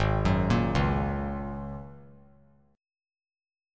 G#9sus4 Chord
Listen to G#9sus4 strummed